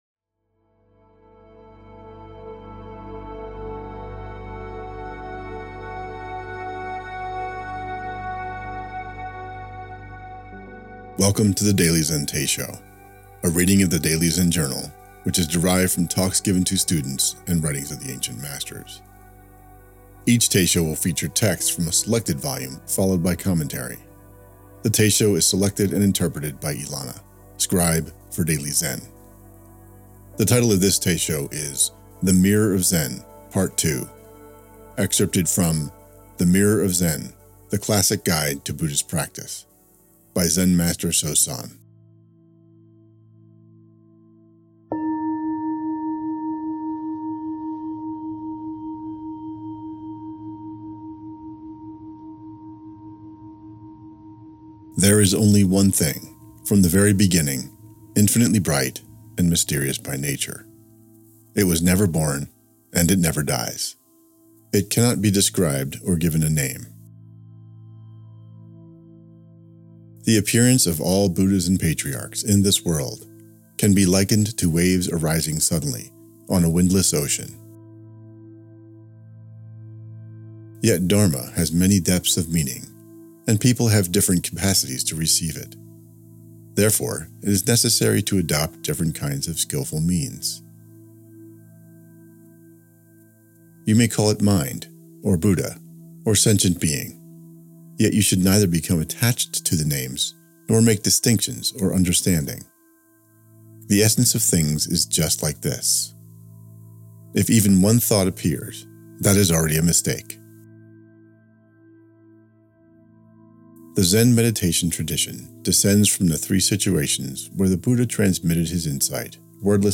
The Daily Zen Teisho